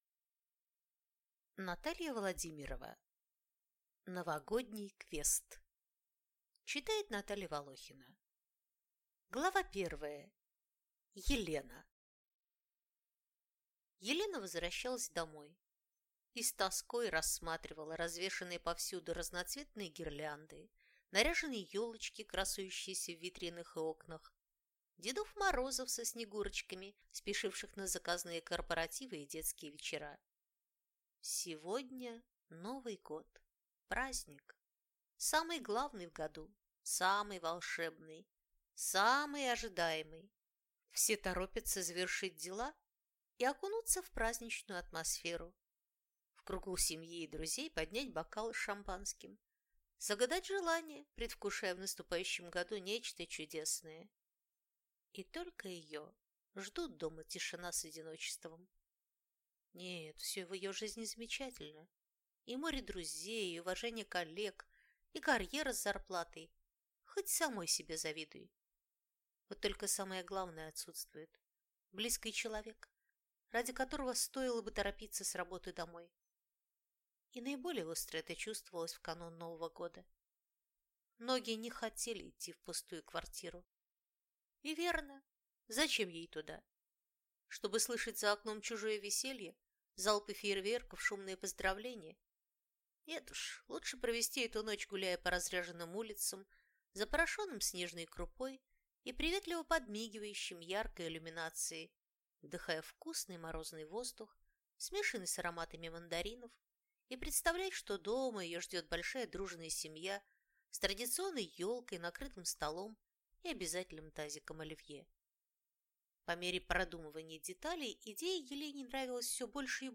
Aудиокнига Новогодний квест